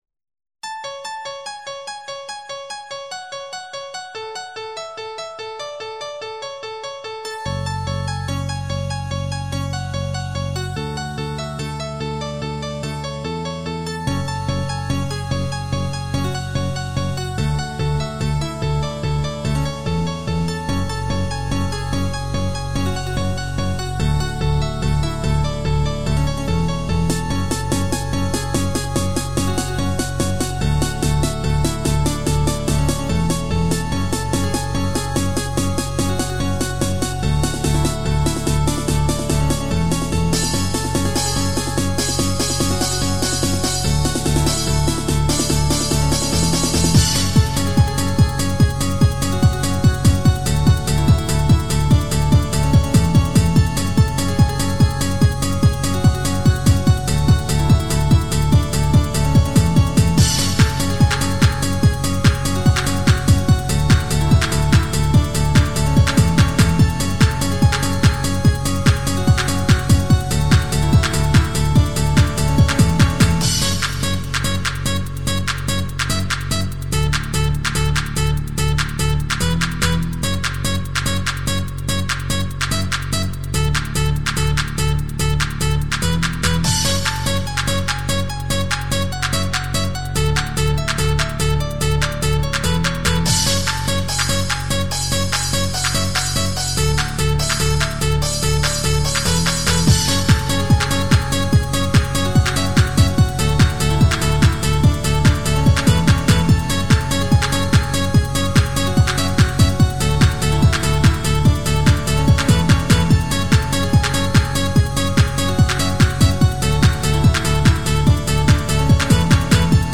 trance mix
• Jakość: 44kHz, Stereo